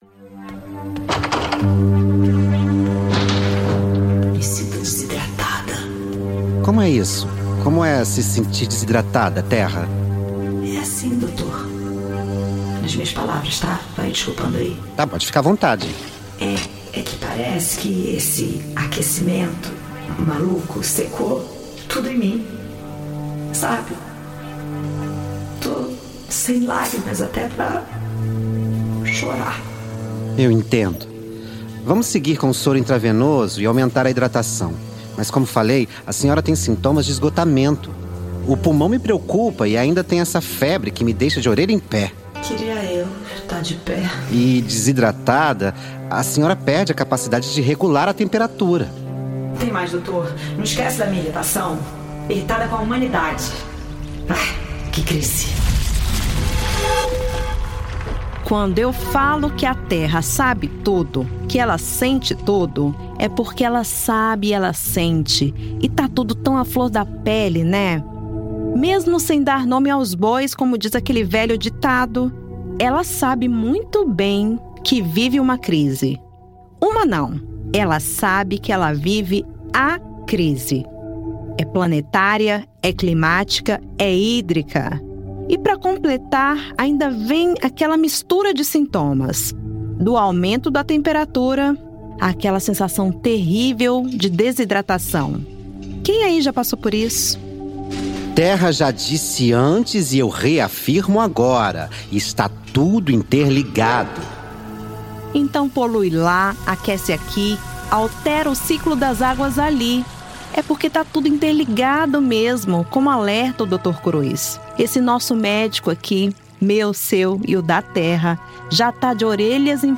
Spot